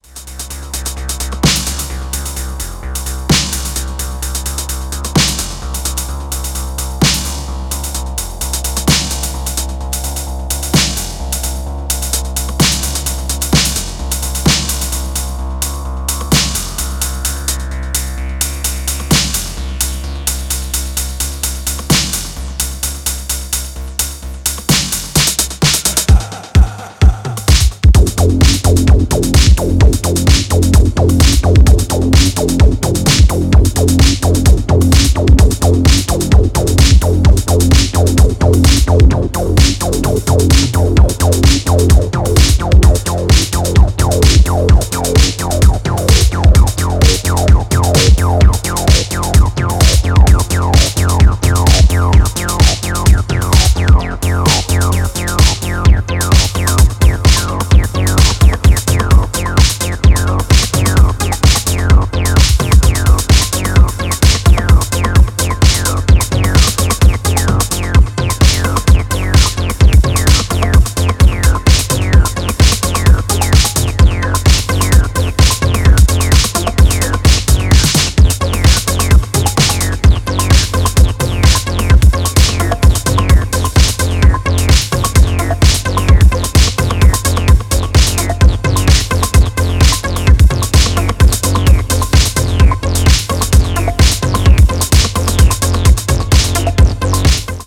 ニュービート感覚も漂わすヘヴィなインダストリアルビート